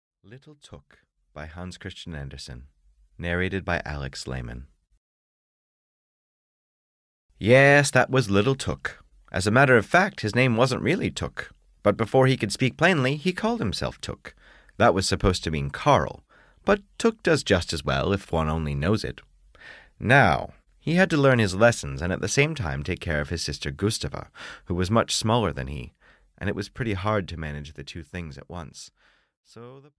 Audio knihaLittle Tuk (EN)
Ukázka z knihy